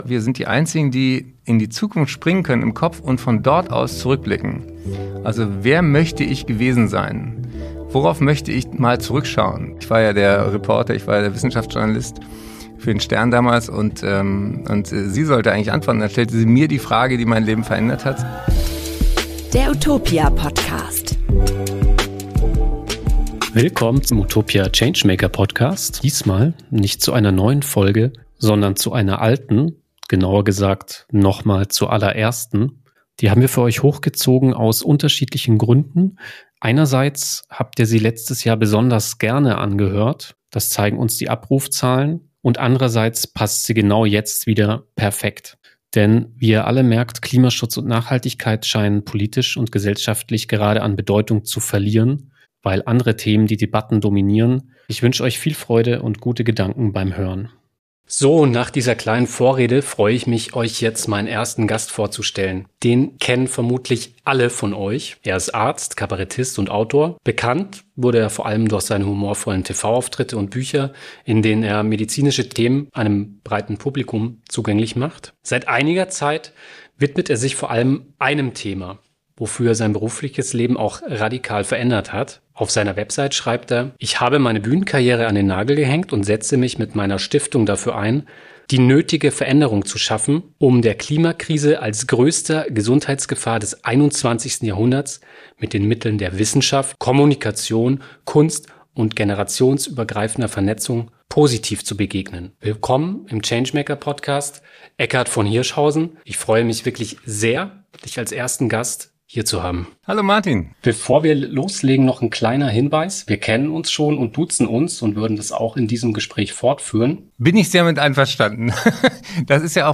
Im Utopia Podcast sprechen unsere Redakteur:innen über Erkenntnisse aus ihren Recherchen und führen Interviews mit angesehenen Expert:innen. Energiewende, vegane Ernährung, nachhaltige Finanzen oder die Mobilität der Zukunft – mal alltagsnah, mal visionär.